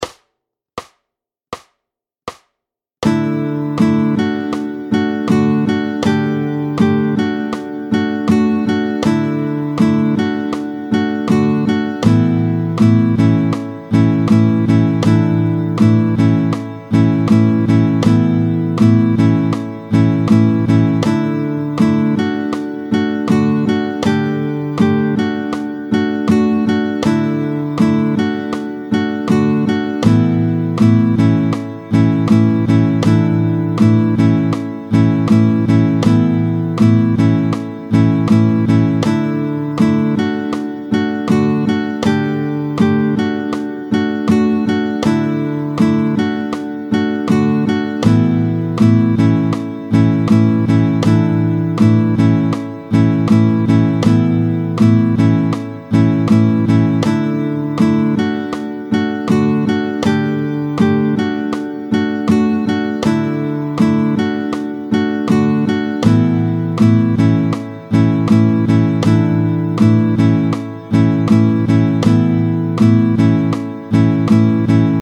25-01 Les trucs de folkeux, tempo 80
L’accord n’est plus « triste » ou « gai » pour parler simple, il est suspendu, il a quelque chose d’inachevé, d’imprécis, d’instable ou mieux, d’aérien.